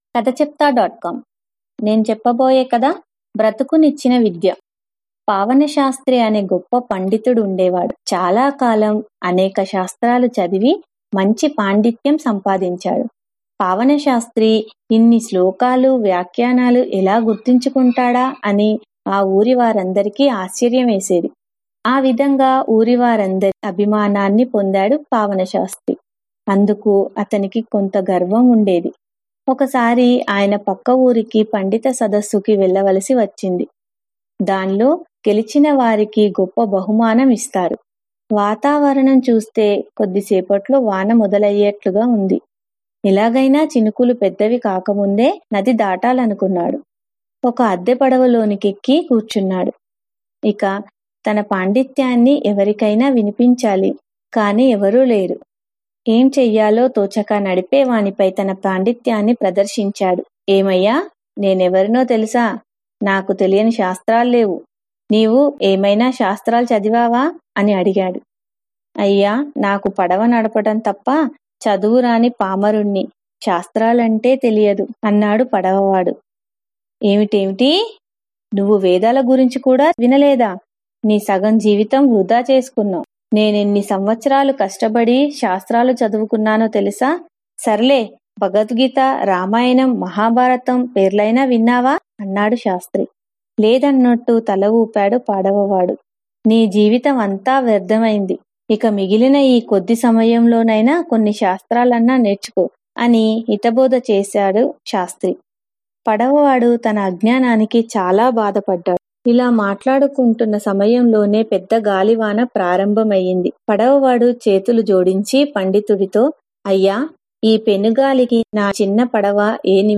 Telugu Audio Stories for Kids